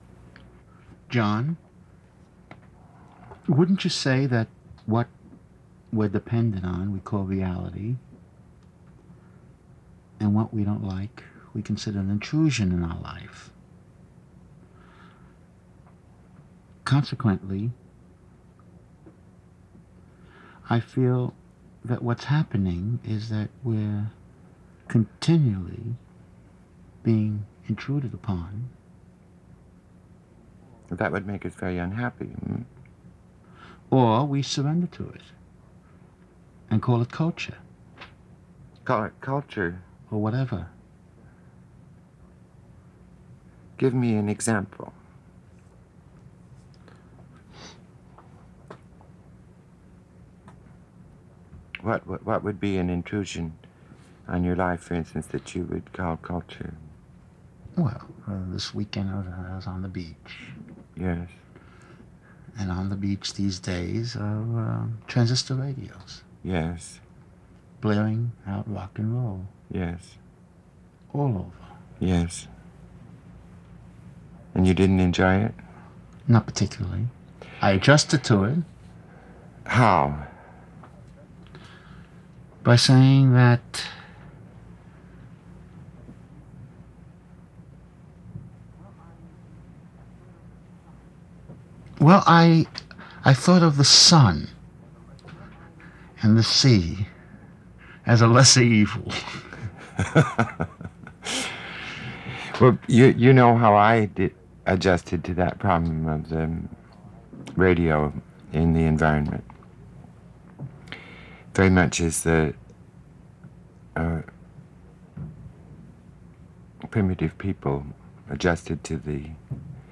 We listen to a bit of Radio Happening I at both the start and finish of the program.